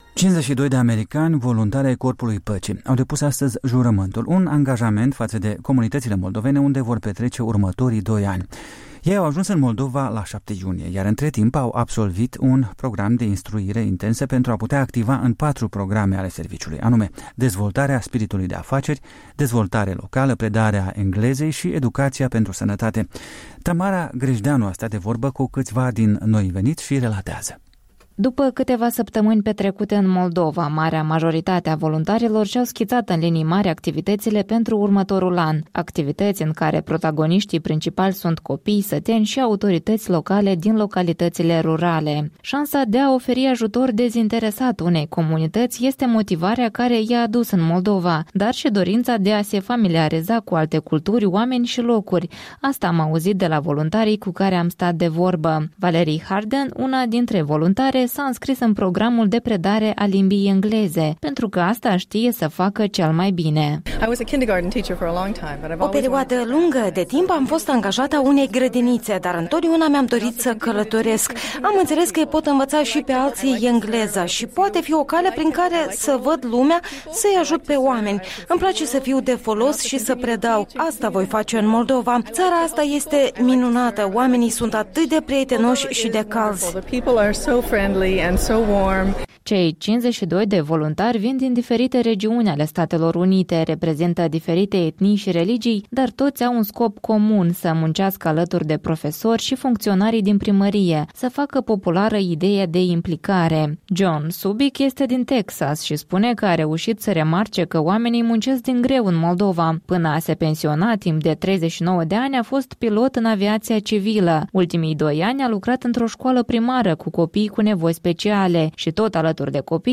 Corespondenta noastră a stat de vorbă cu câțiva dintre voluntarii noi veniți și relatează: